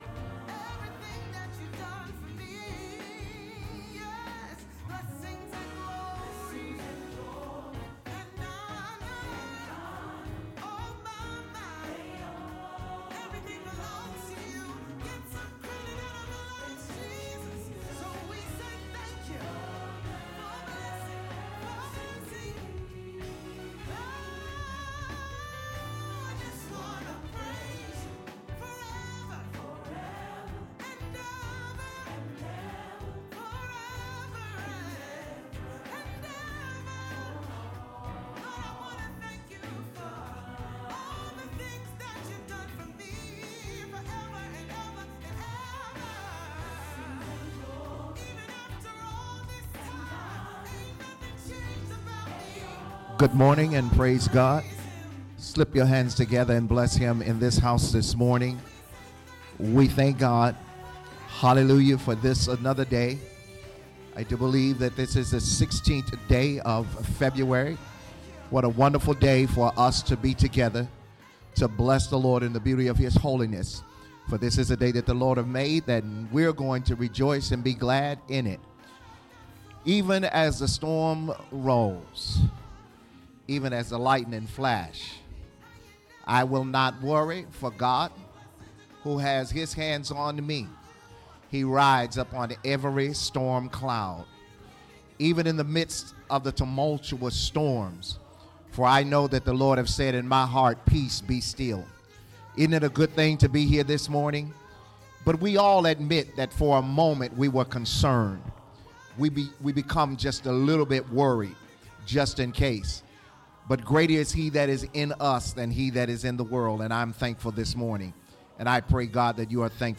Sunday Service. ASGDC 2 - Stay There.
Nations Harvest Church Assembly Podcast … continue reading 37 एपिसोडस # Religion # Live Recordings # Christianity # Video